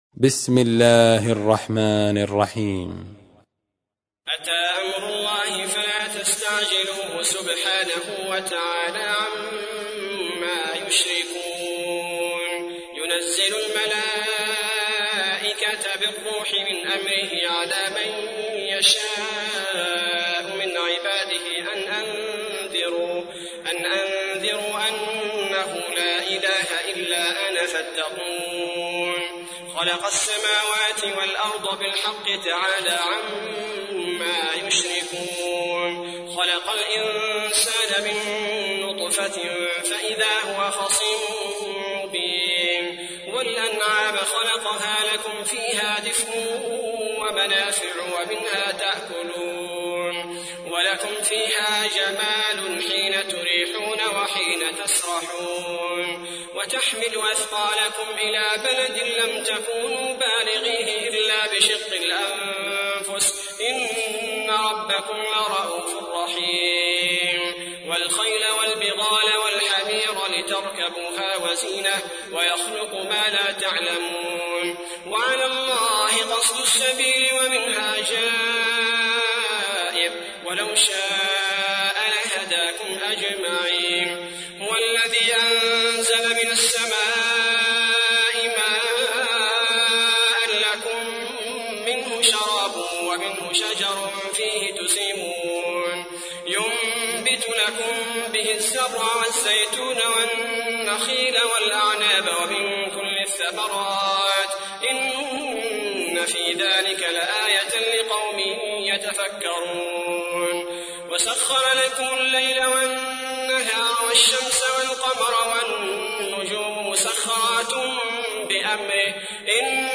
تحميل : 16. سورة النحل / القارئ عبد البارئ الثبيتي / القرآن الكريم / موقع يا حسين